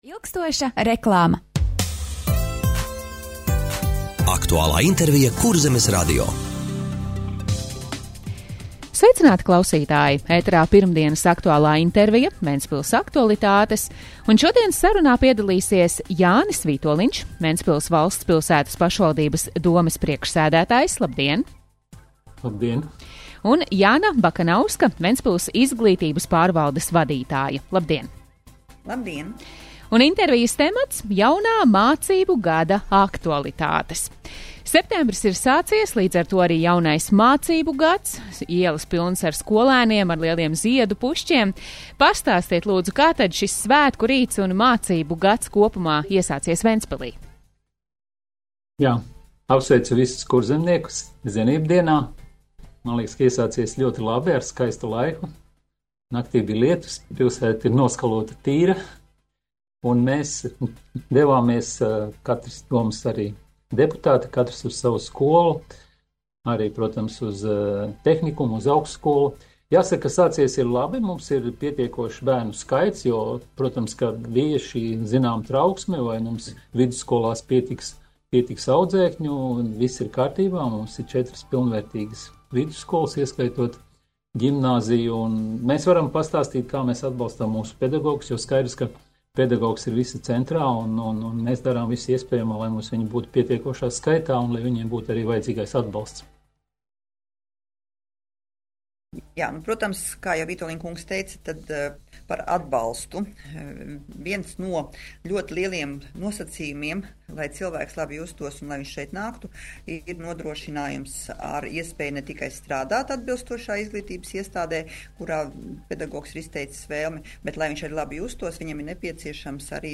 Radio saruna - Jaunā mācību gada aktualitātes - Ventspils